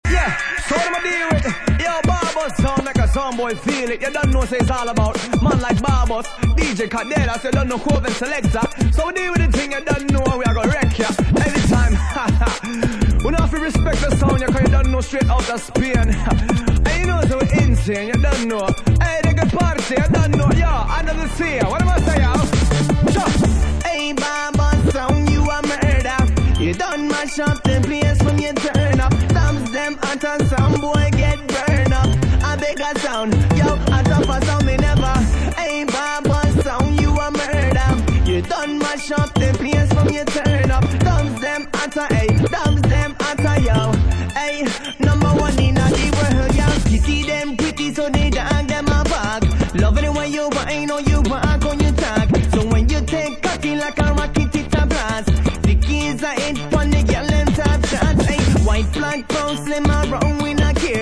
UK Garage / Breaks, Hip Hop/Dj Tools